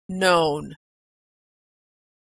Words with Silent Letters - Examples - k - g - Authentic American Pronunciation
Silent k
verb-known.mp3